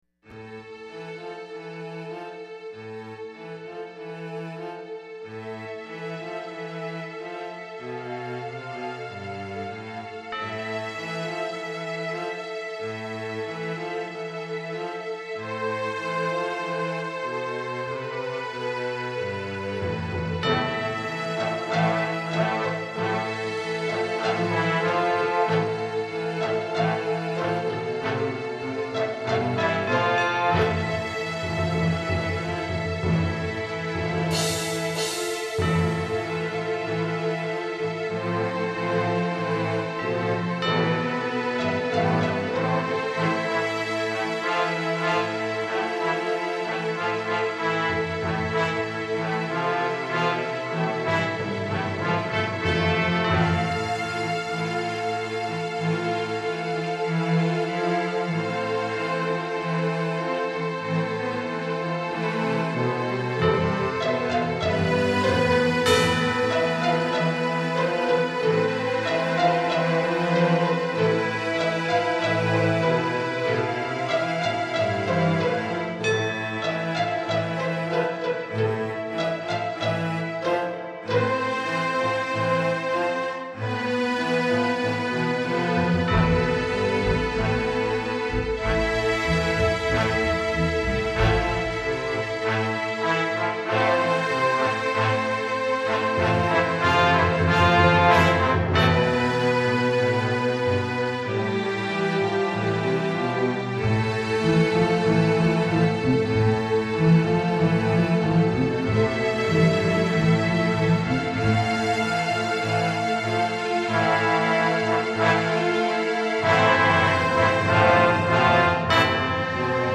Classical-Acoustic version